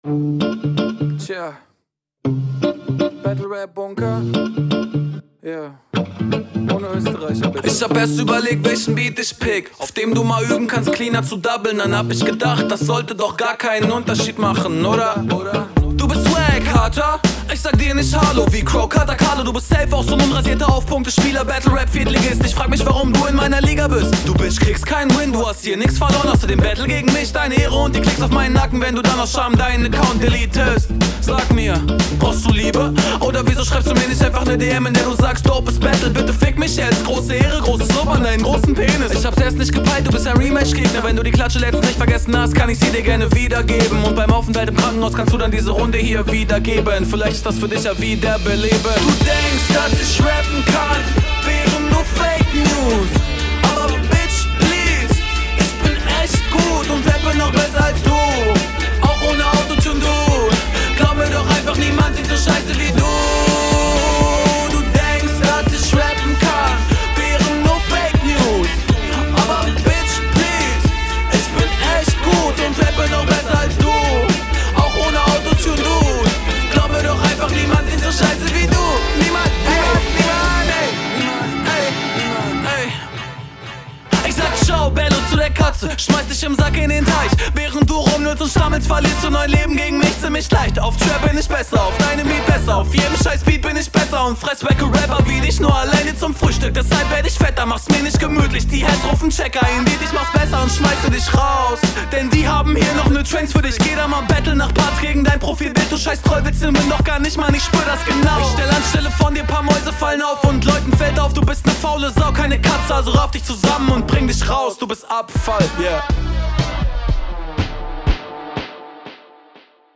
Deine Mische ist sehr nice, Flow und stimmeneinsatz wieder onpoint.